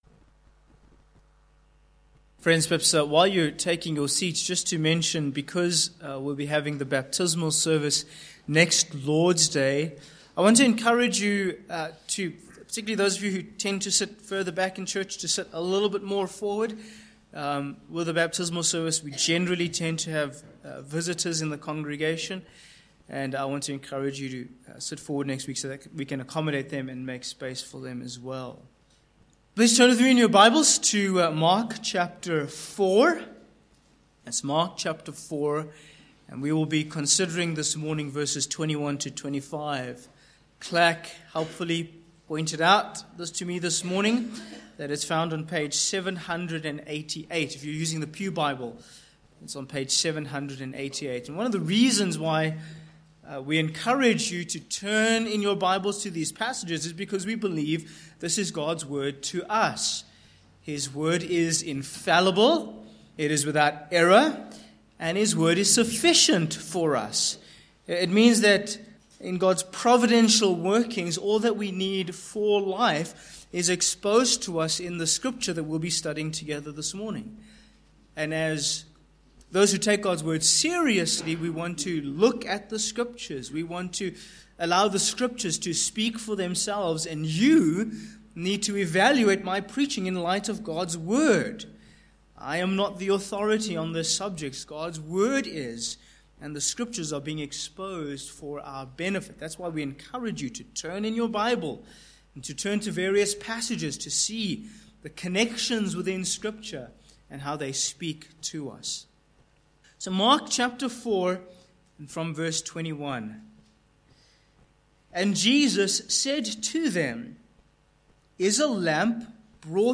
Sermon points: 1. Hidden Lamps v21-2